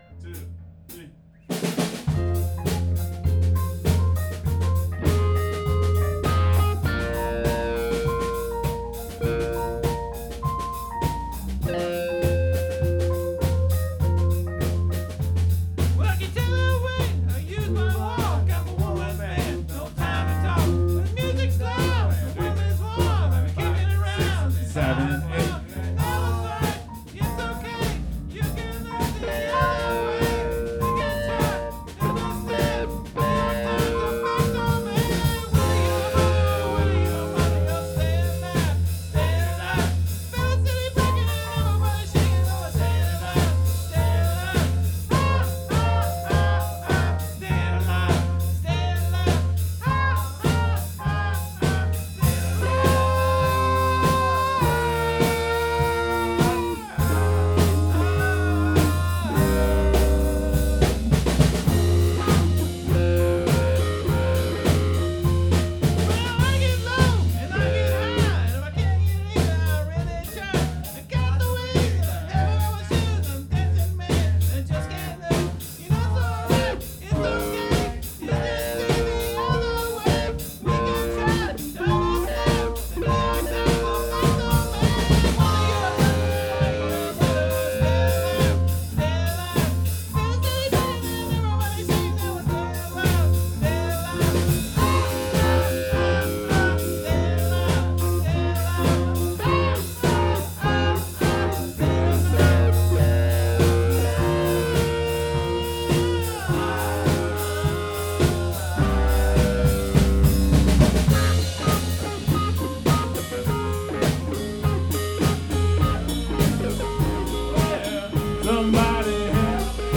Jam